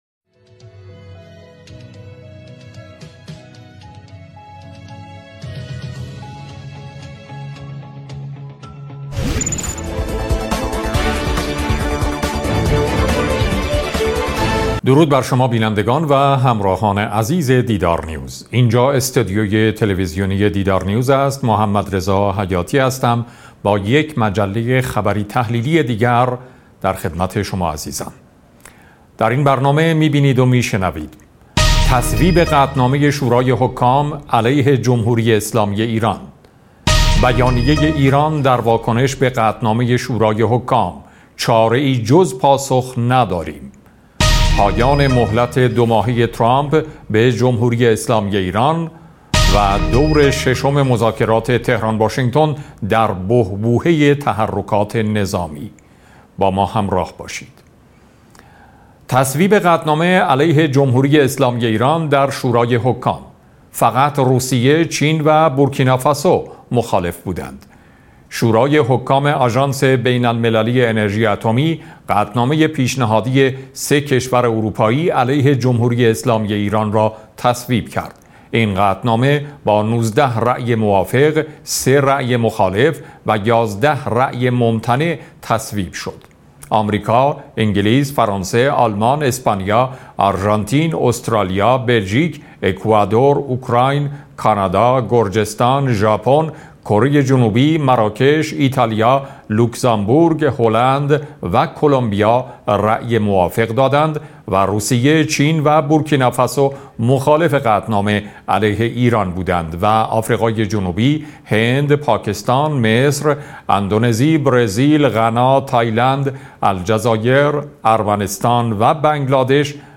صدای مجله خبری تحلیلی دیدارنیوز با اجرای محمدرضا حیاتی و با حضور کارشناسان و صاحب نظران را می‌توانید اینجا گوش دهید.